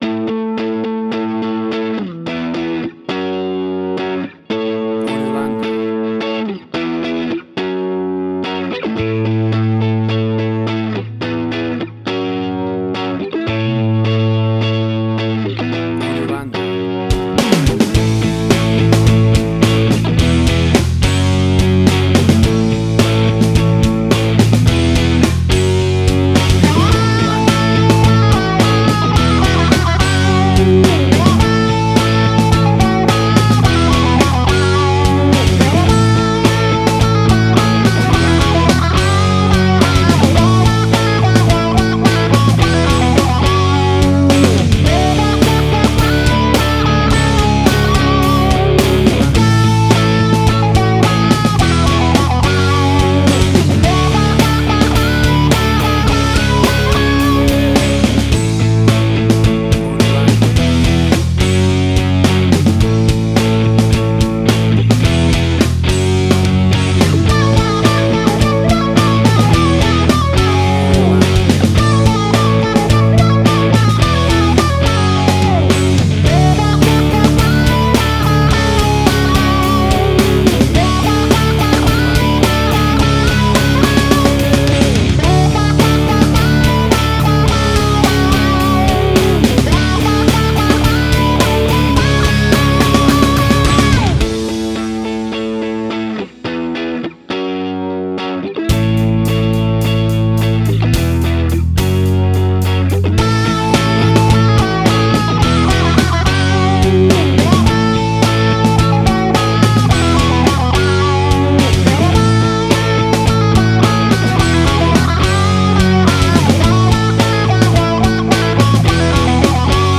Hard Rock 102.jpg
Hard Rock
Heavy Metal.
Tempo (BPM): 107